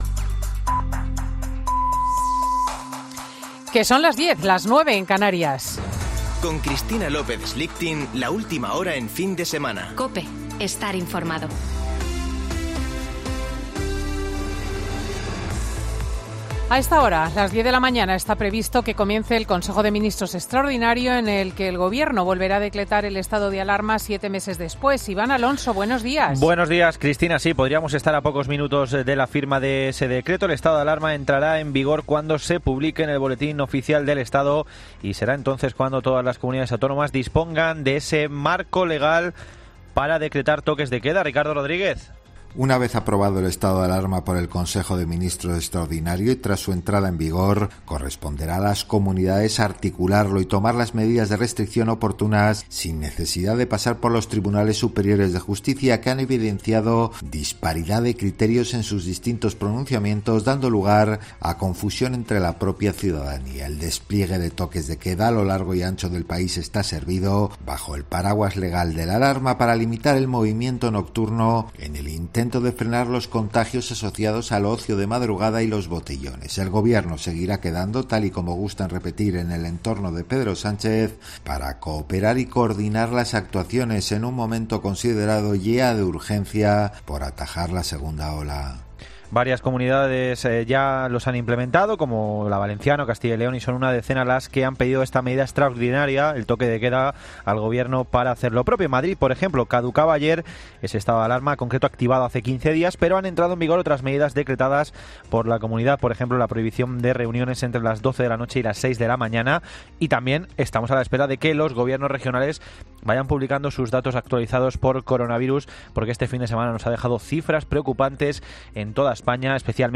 Boletín de noticias COPE del 25 de octubre de 2020 a las 10.00 horas